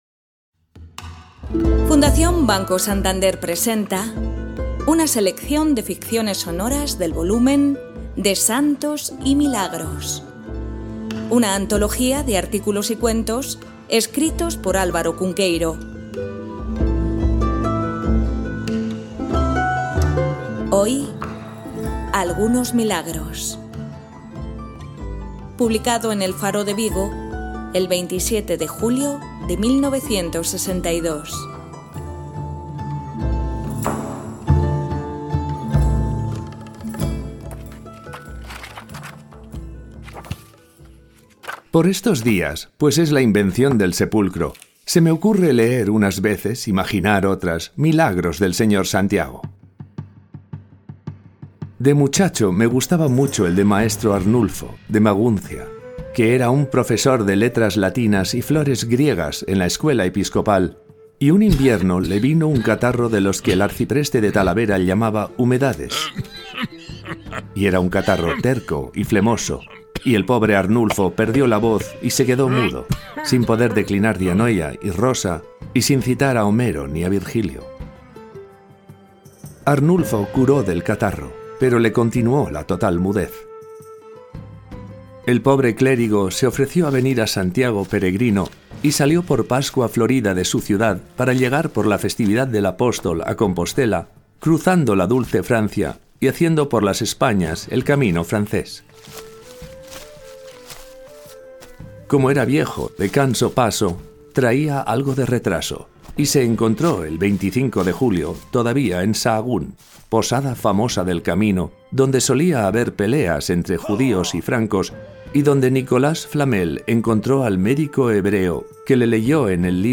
Ficciones sonoras